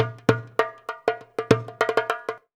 100DJEMB29.wav